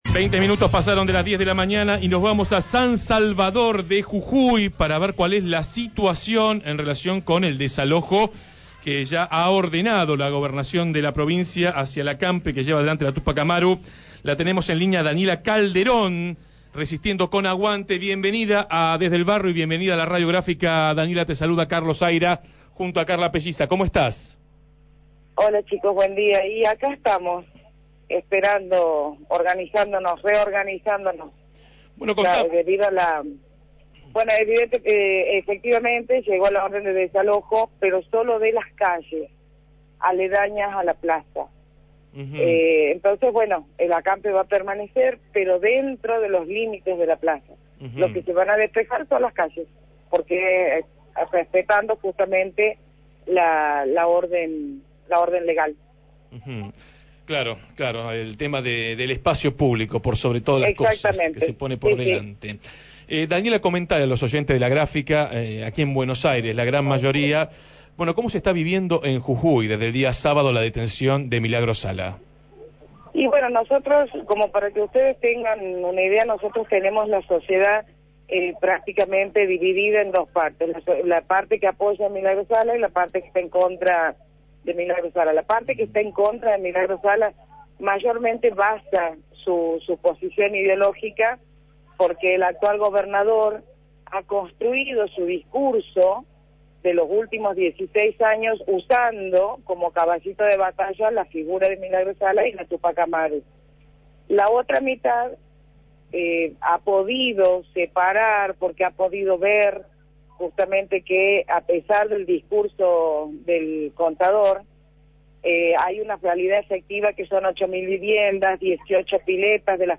dialogó con Desde el Barrio desde la plaza Belgrano donde se realiza el acampe de la Tupac Amaru.